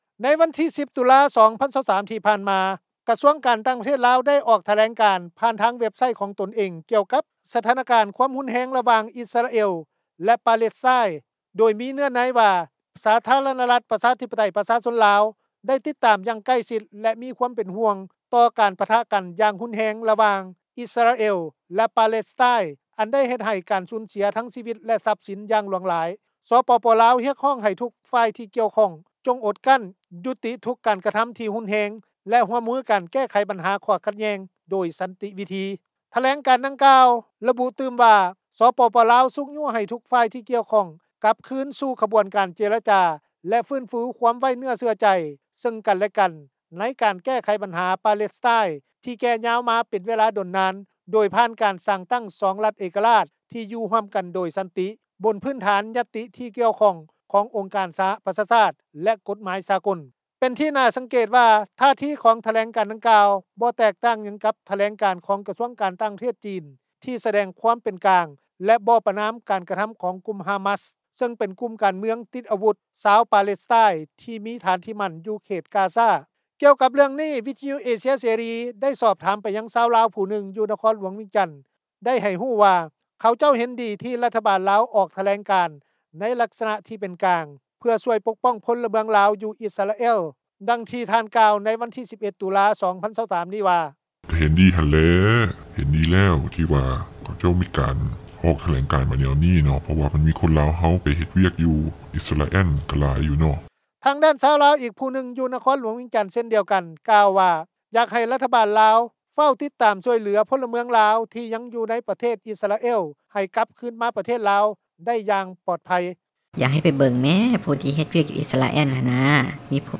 ນັກຂ່າວ ພົລເມືອງ
ກ່ຽວກັບເຣື່ອງນີ້, ວິທຍຸເອເຊັຽເສຣີ ໄດ້ສອບຖາມຄວາມຄິດເຫັນຊາວລາວ ຜູ້ນຶ່ງ ຢູ່ນະຄອນຫຼວງວຽງຈັນ ໂດຍຊາວບ້ານຜູ້ນີ້ກ່າວວ່າ ເຂົາເຈົ້າເຫັນດີ ທີ່ຣັຖບານລາວ ອອກຖແລງການ ໃນລັກສະນະທີ່ເປັນກາງ ເພື່ອຊ່ອຍປົກປ້ອງ ພົລເມືອງລາວ ຢູ່ອິສຣາແອລ.
ຊາວລາວ ອີກຜູ້ນຶ່ງ ຢູ່ນະຄອນຫຼວງວຽງຈັນ ກ່າວວ່າ ຢາກໃຫ້ຣັຖບານລາວ ຟ້າວຕິດຕາມຊ່ອຍເຫຼືອ ພົລເມືອງລາວ ທີ່ຍັງຢູ່ໃນປະເທດອິສຣາແອລ ໃຫ້ໄດ້ກັບຄືນປະເທດລາວ ໄດ້ຢ່າງປອດໄພ.